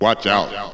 VOX SHORTS-1 0009.wav